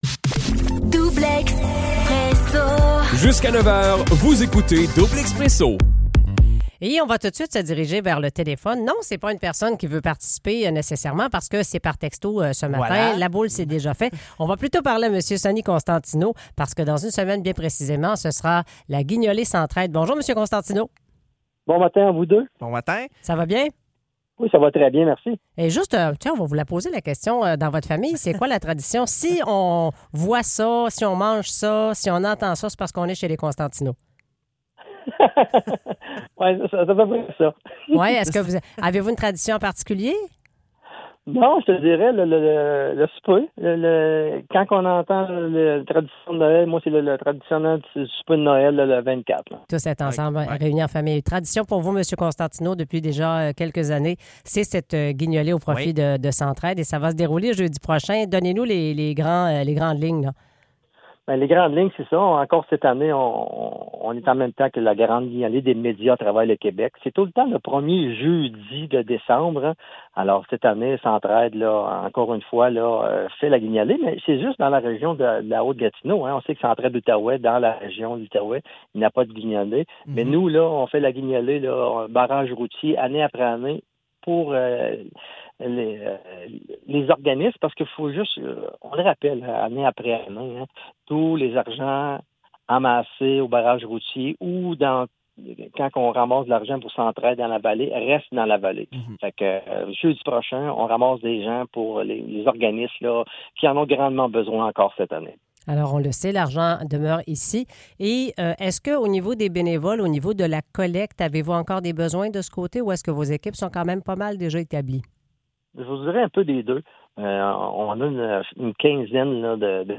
Toutes les entrevues
Écoutez ou réécoutez les différentes entrevues réalisées sur les ondes de CHGA.